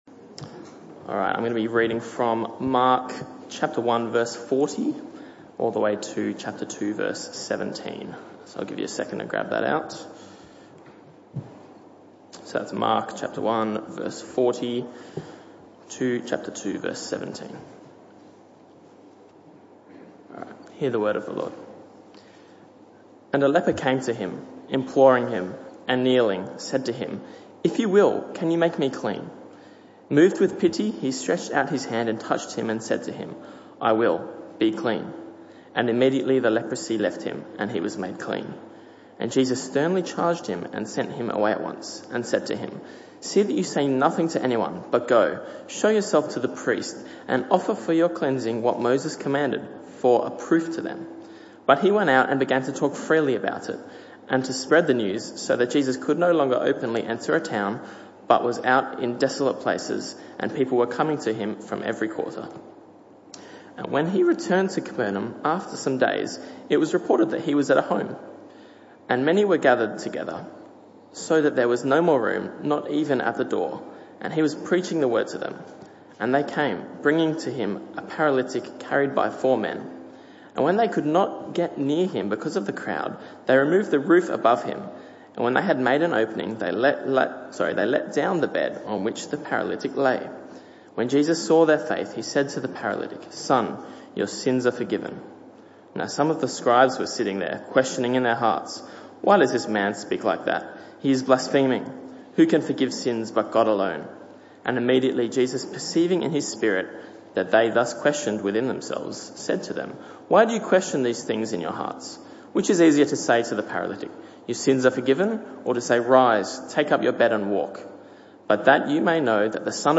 This talk was the last in the PM Service series entitled Who Is Jesus?